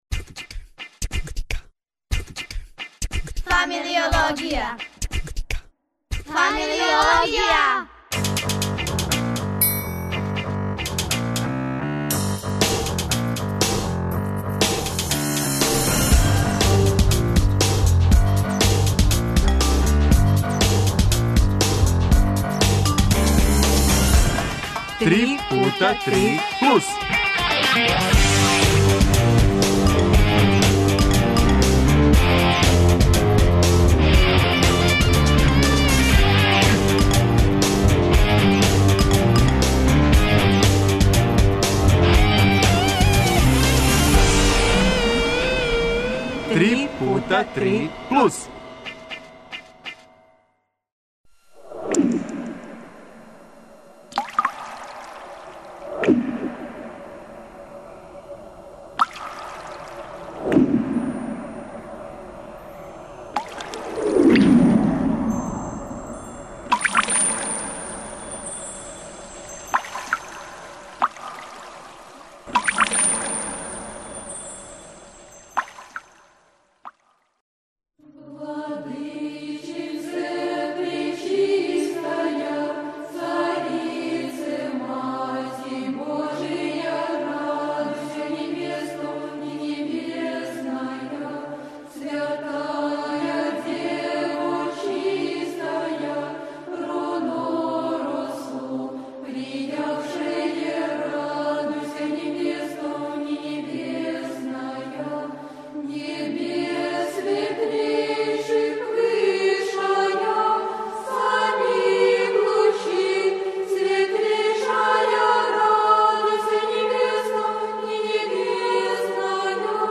А, калиграфија свакако представља важан део трајања и лица једног народа. О томе наши гости, деца и одрасли.